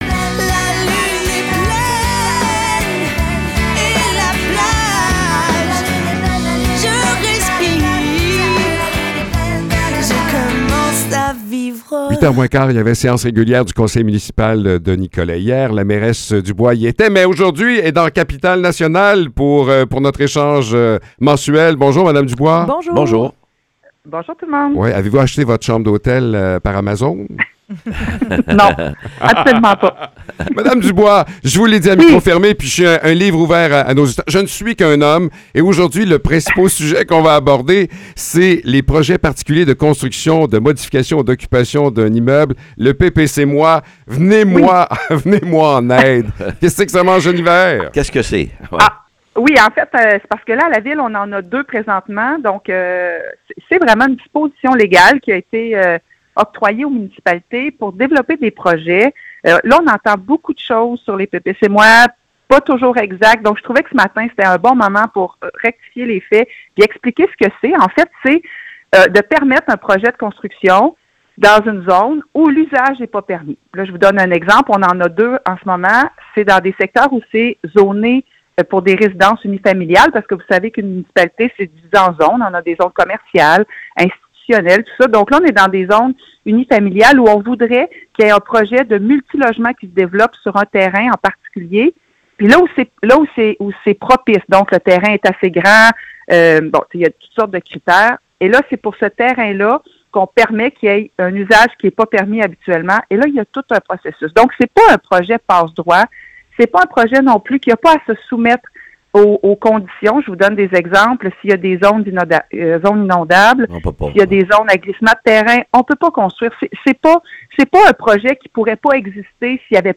Échange avec la mairesse de Nicolet
Geneviève Dubois, mairesse de Nicolet, nous parle des dernières nouvelles de la ville.